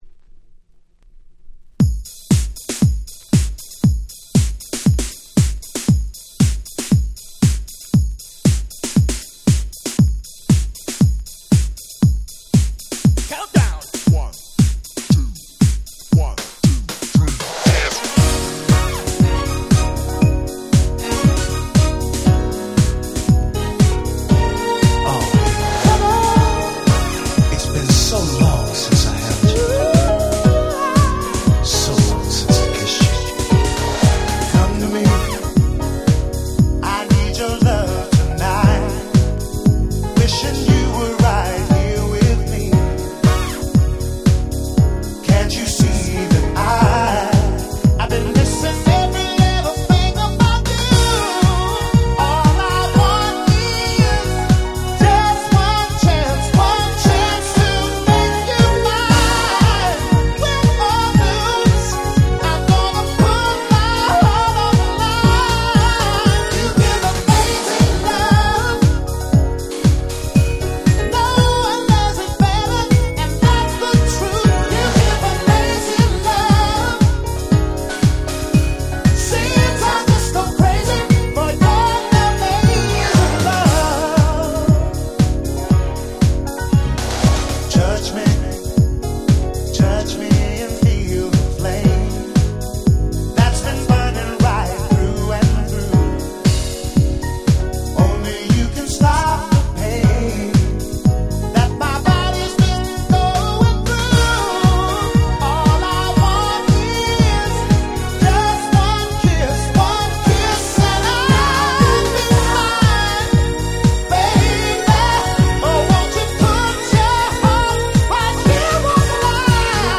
91' Very Nice R&B / ブラコン！！
疾走感のあるBPMに込み上げるVocal、もう最高です！！